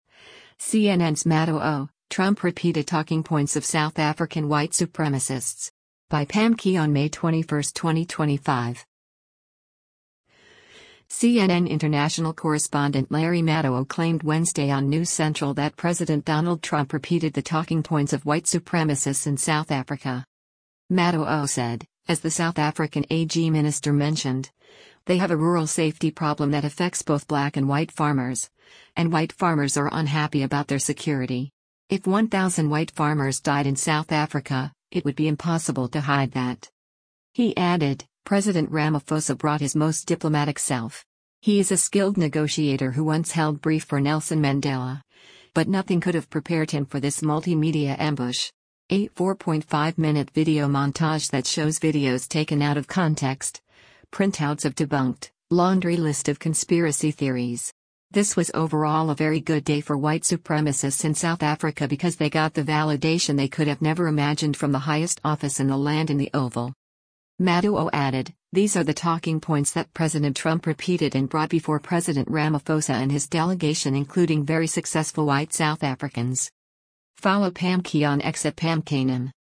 CNN international correspondent Larry Madowo claimed Wednesday on “News Central” that President Donald Trump repeated the talking points of “white supremacists in South Africa.”